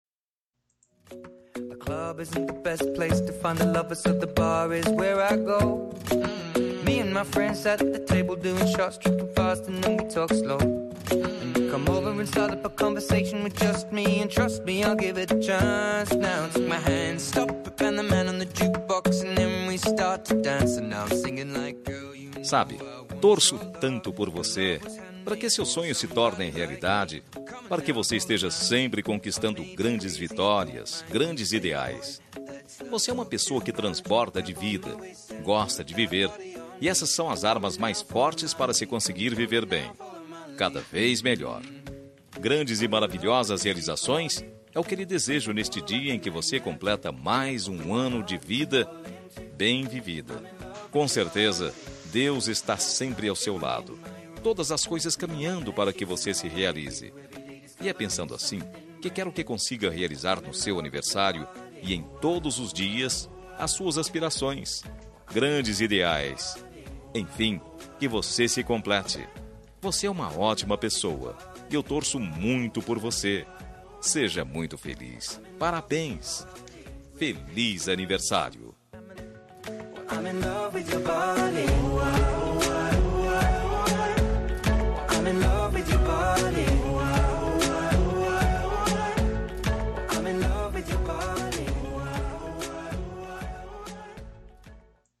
Telemensagem de Pessoa Especial – Voz Masculina – Cód: 02345